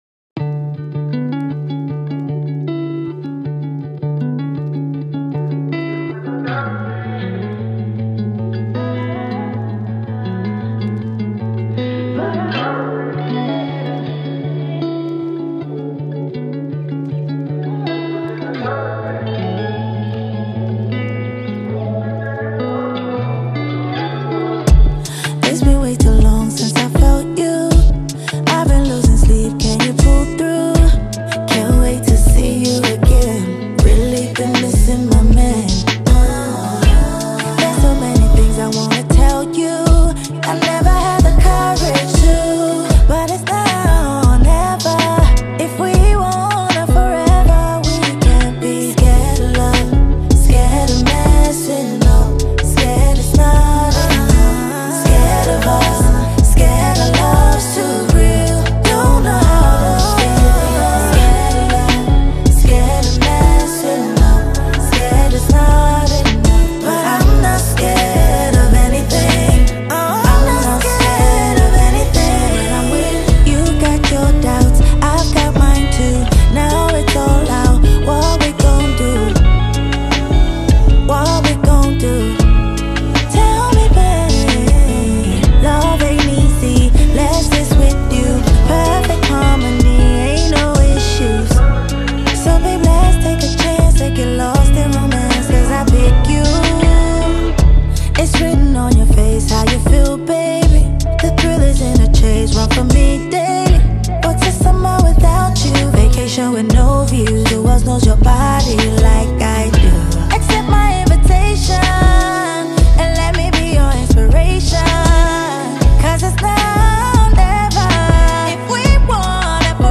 Afrobeats
With a smooth blend of Afropop, R&B, and soul